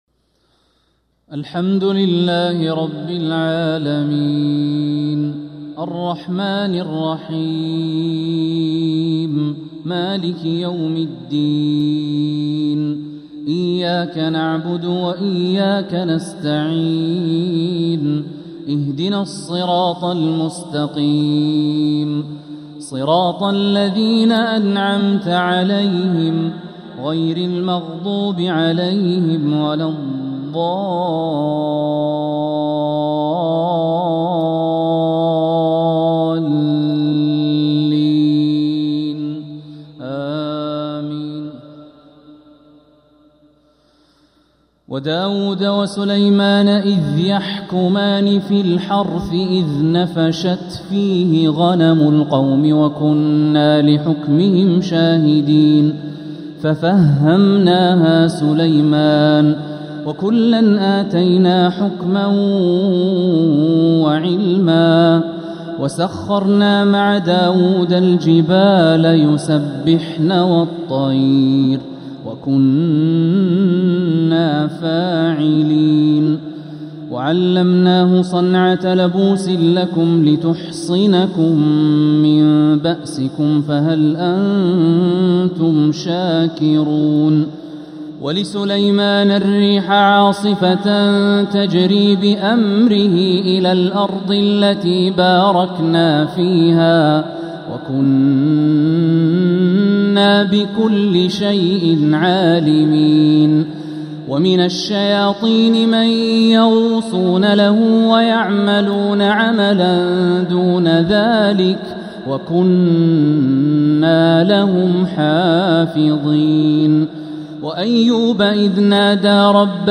فجر الاثنين 12 محرم 1447هـ من سورة الأنبياء 78-90 | Fajr prayer from Surah Al-Anbiya 7-7-2025 🎙 > 1447 🕋 > الفروض - تلاوات الحرمين